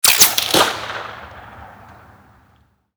Railgun_Far_02.ogg